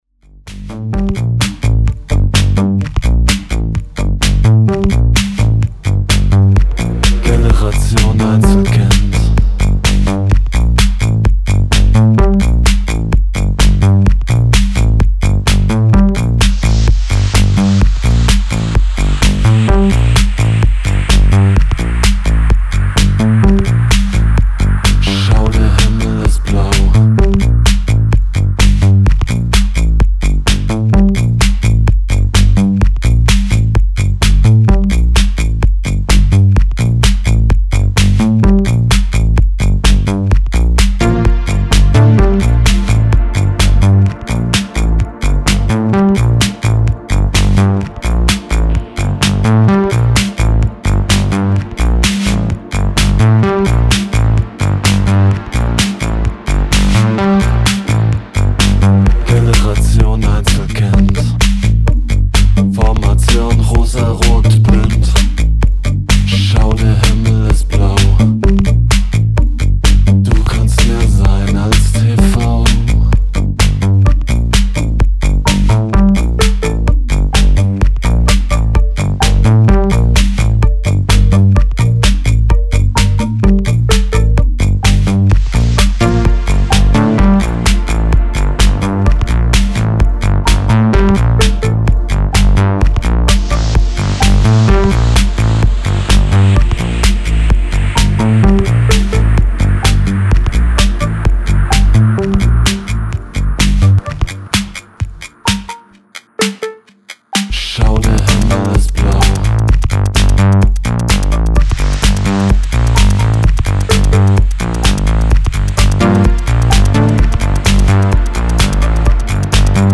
Style: Tech House / Minimal